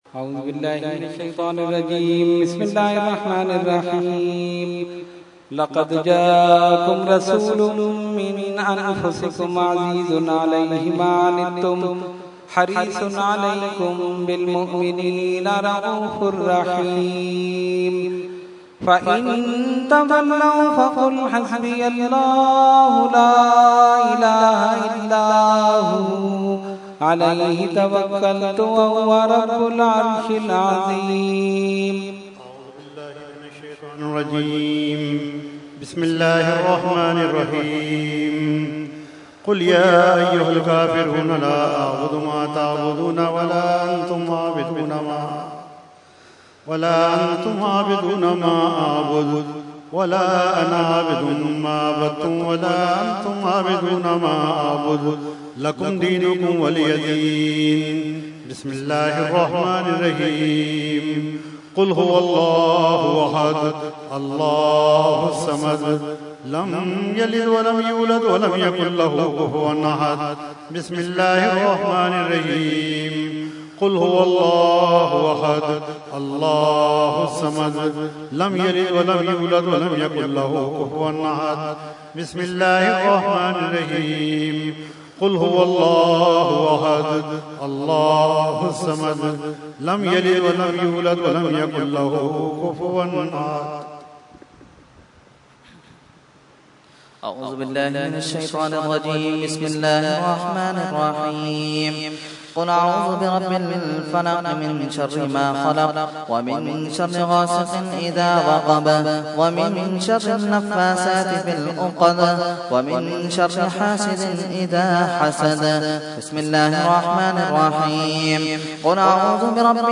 Category : Fatiha wa Dua | Language : ArabicEvent : Urs Ashraful Mashaikh 2014